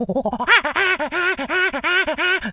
monkey_chatter_angry_05.wav